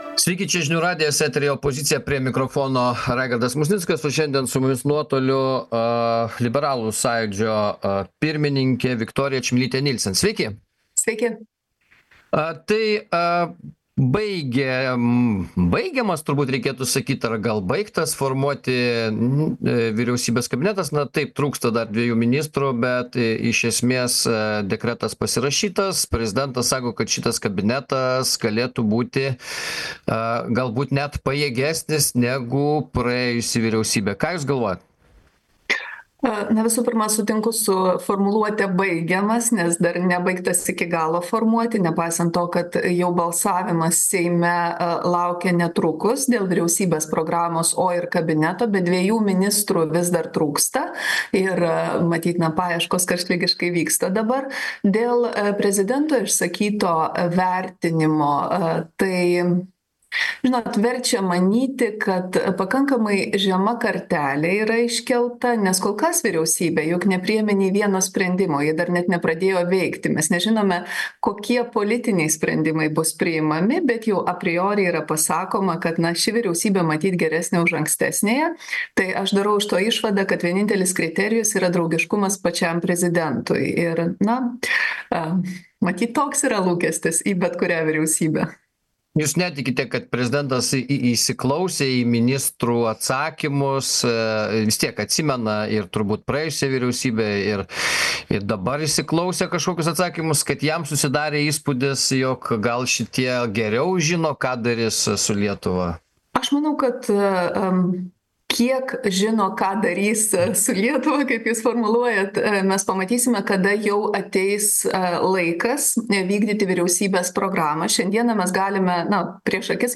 Laidoje dalyvauja liberalų sąjūdžio pirmininkė Viktorija Čmilytė-Nielsen.